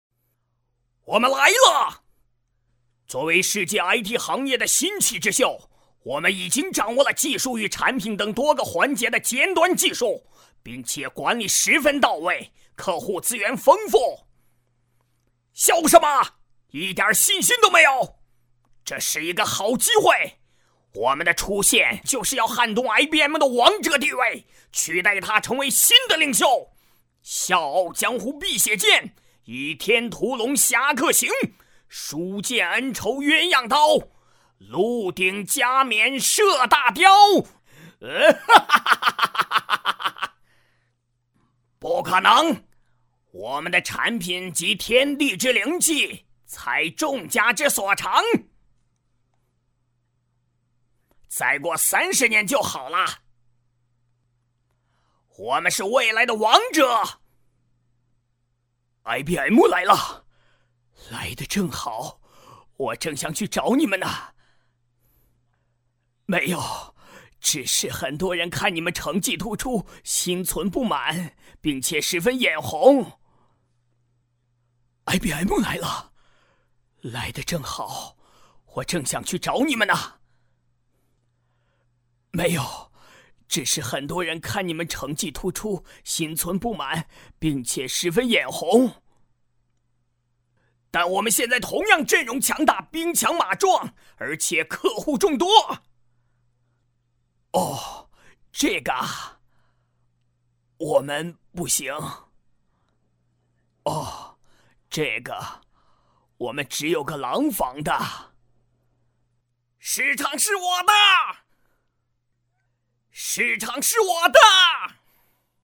动画配音是对动画漫画人物、电影角色，用声音配合人物的面部表情以及情绪变化进行完美的声音演绎。
男声配音